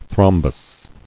[throm·bus]